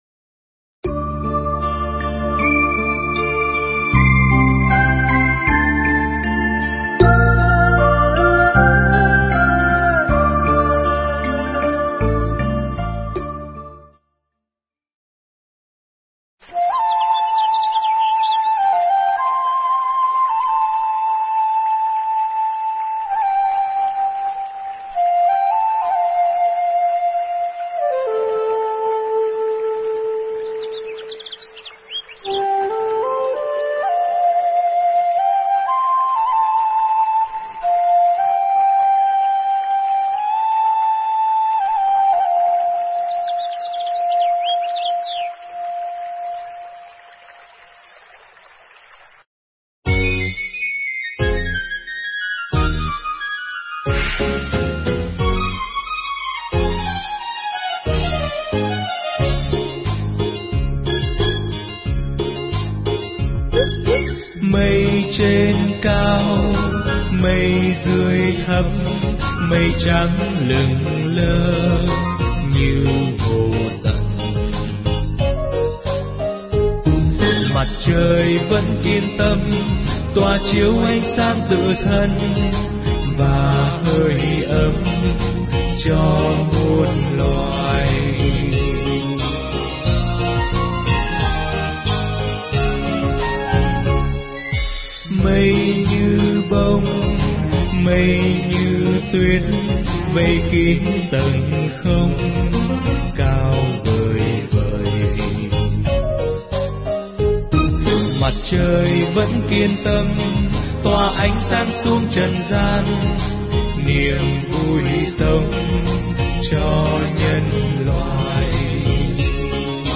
Pháp thoại Hạt Nước Mắt Không Thật Phần 2